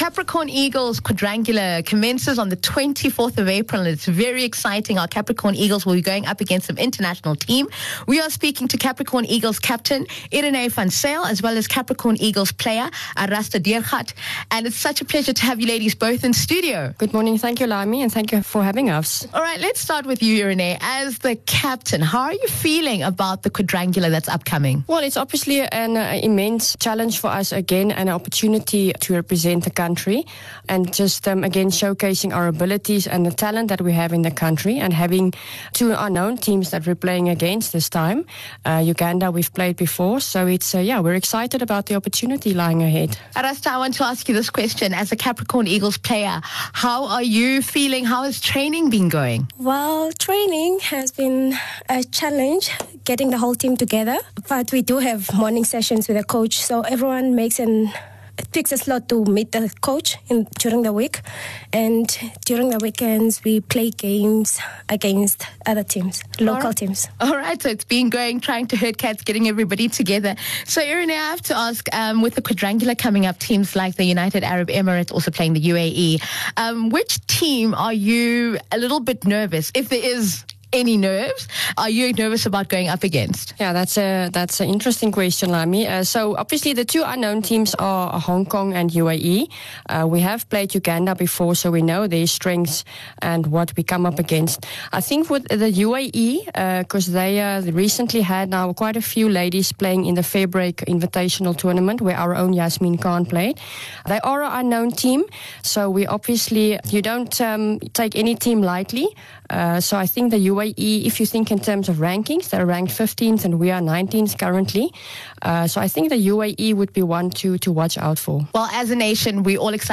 28 Apr Cricket Capricorn Interview